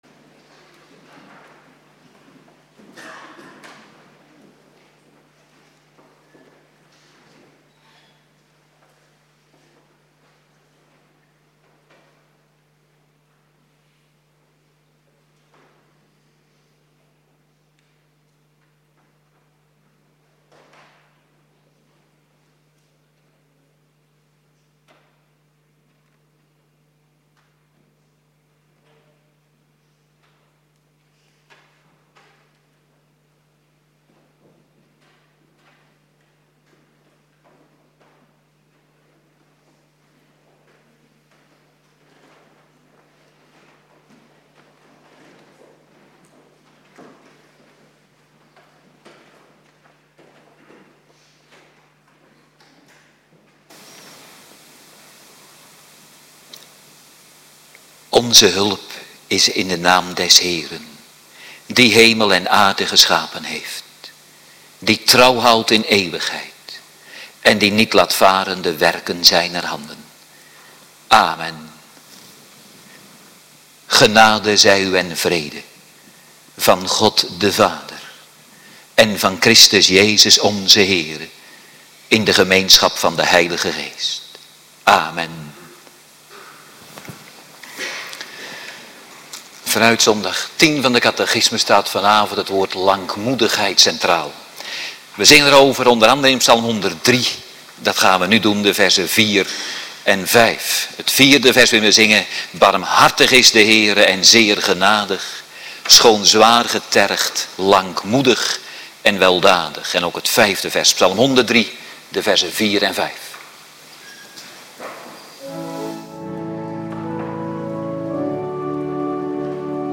Avonddienst
Locatie: Hervormde Gemeente Waarder